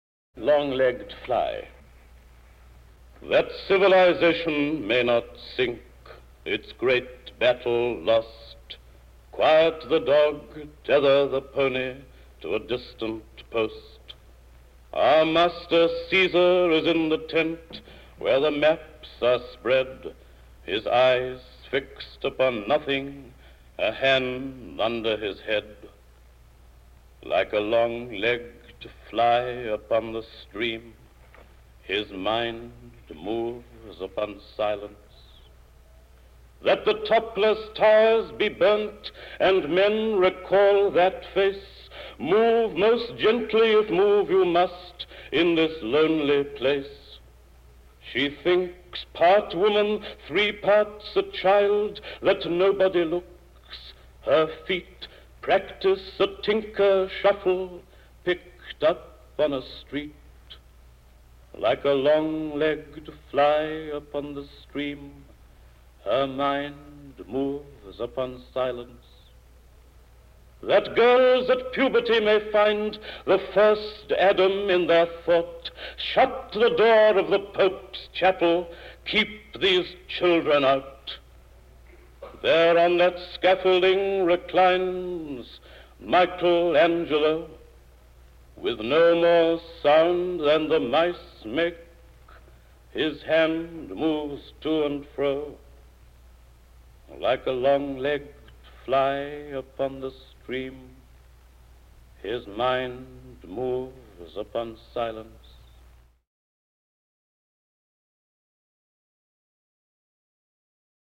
Dylan Thomas Reading "Long-legged Fly" MP34